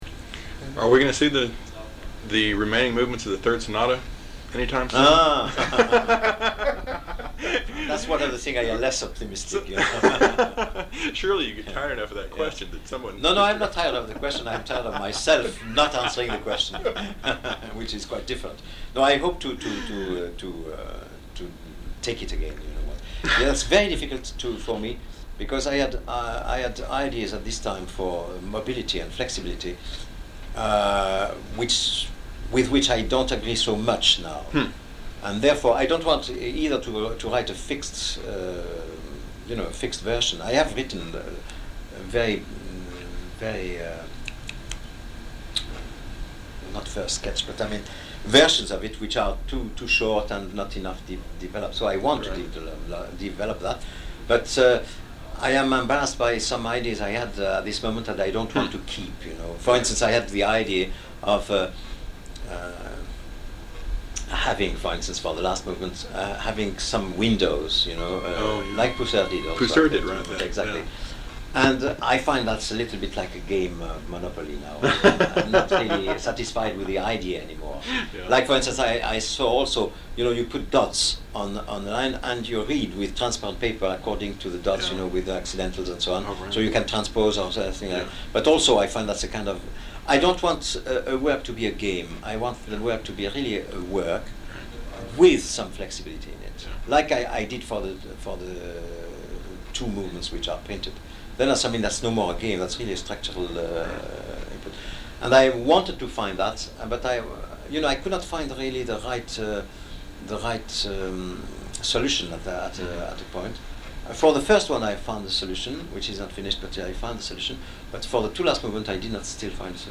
I thought the Boulez interview might be of particular interest. It took place in a hotel room in Chicago on October 27, 1987, when Boulez had come to perform Repons and conduct the Chicago Symphony in his Notations and other works.